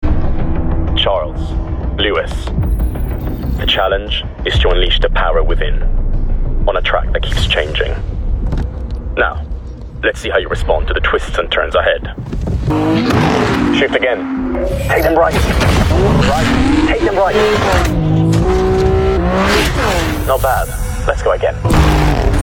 VOICE REEL
British-Italian actor, proficient in English, Italian & Portuguese.